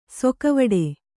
♪ sokavaḍe